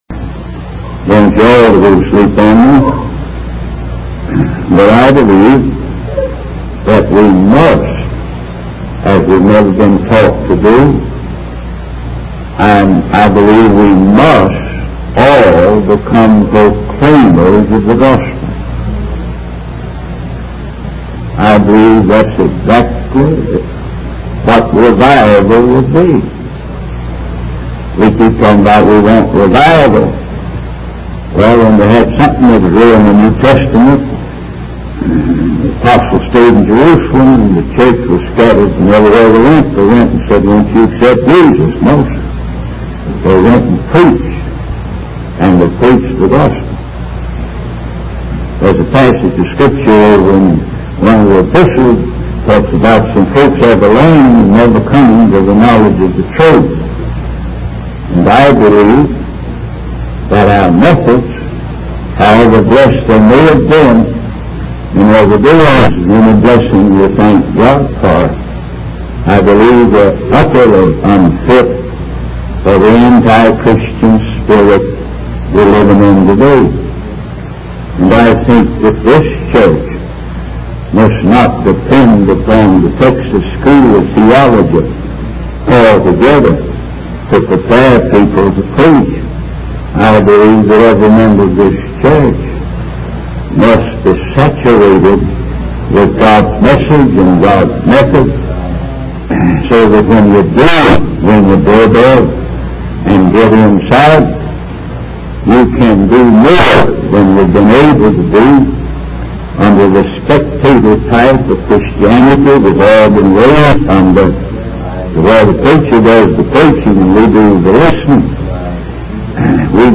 In this sermon, the speaker emphasizes the importance of believing in the Bible and its teachings.